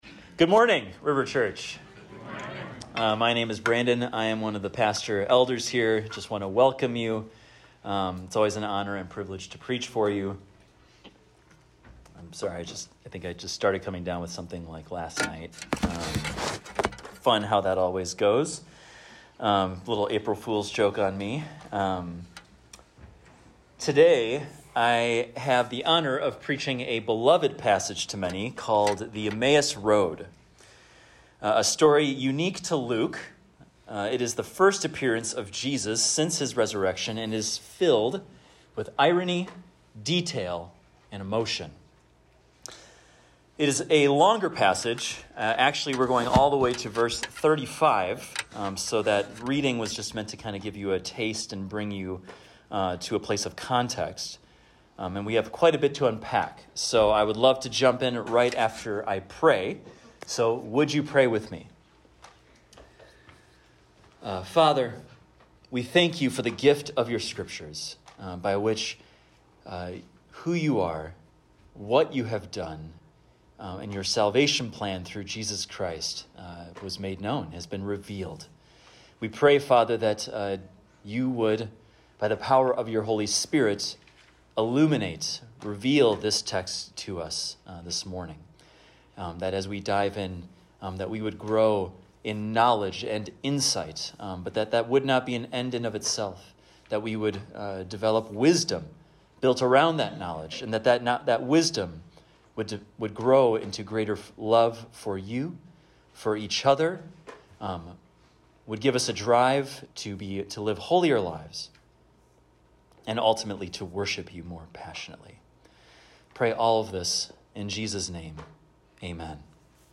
This is a recording of a sermon titled, "The Emmaus Road."